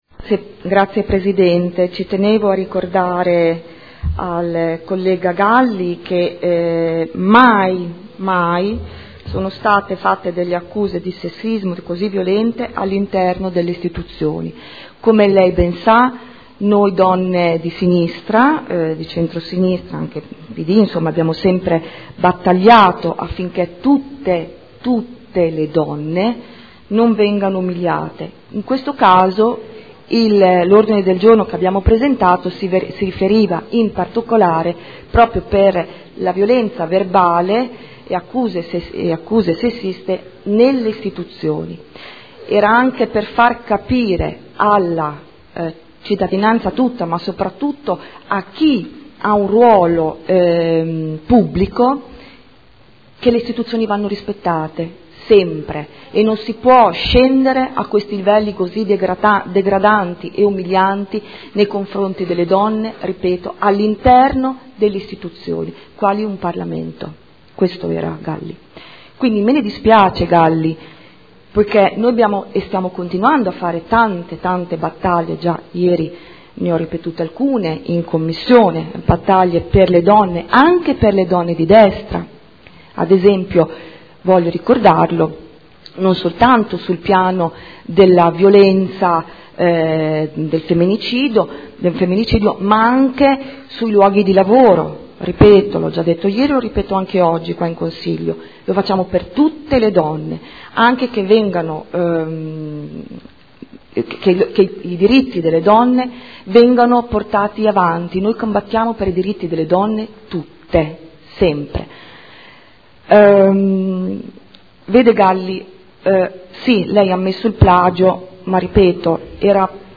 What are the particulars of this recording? Seduta del 27/03/2014. Seduta del 27 marzo 2014